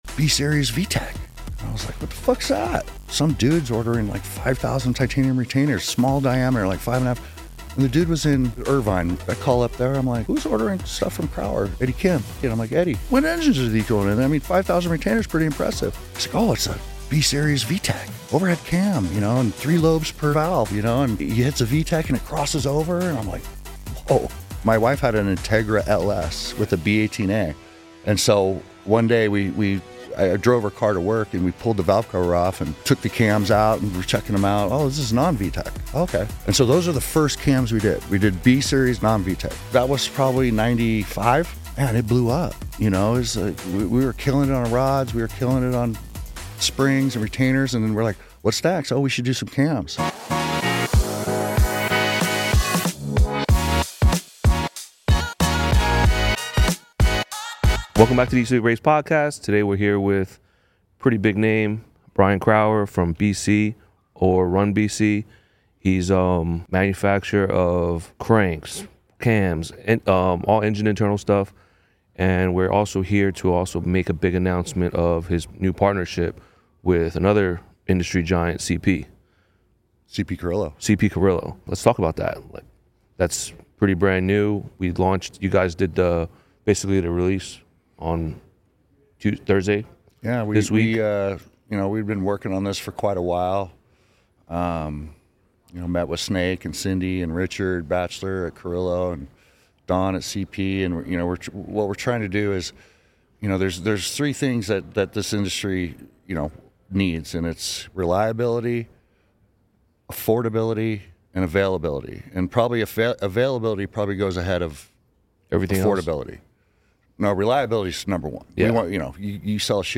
Conversations with the EAT SLEEP RACE crew and guests from the racing industry and more!